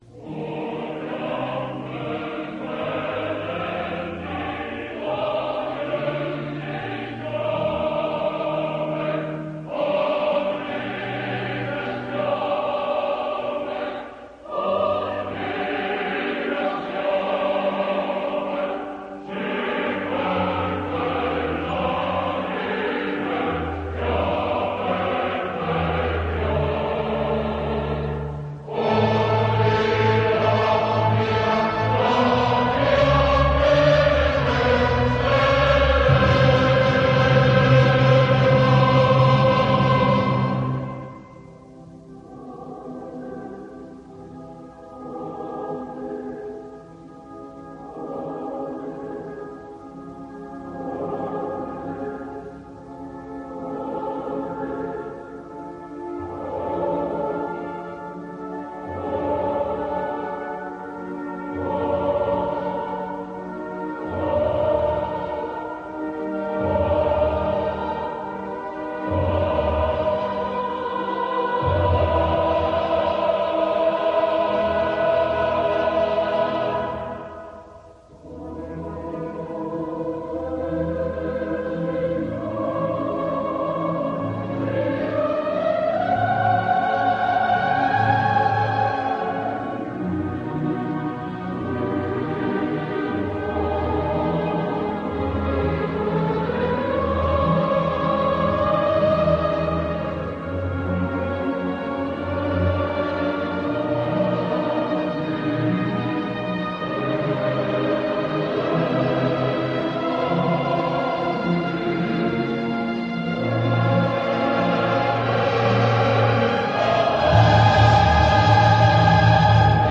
registrazione dal vivo.